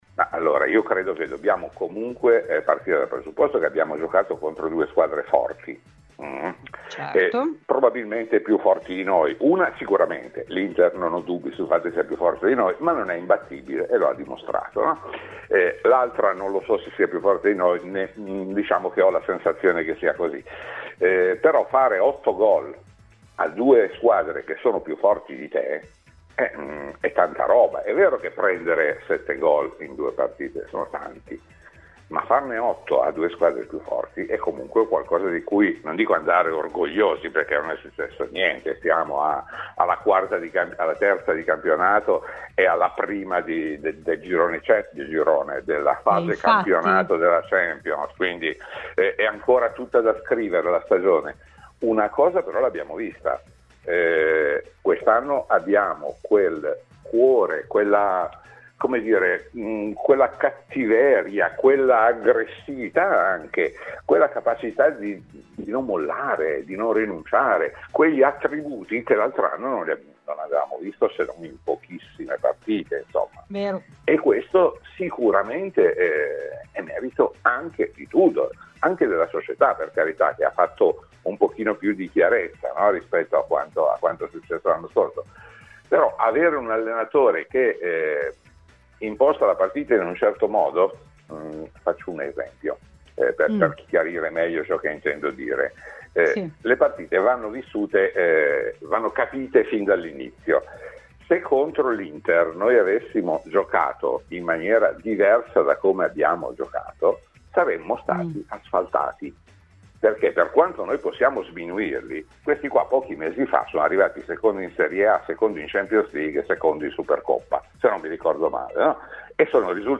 Ospite di "RBN Cafè" su Radio Bianconera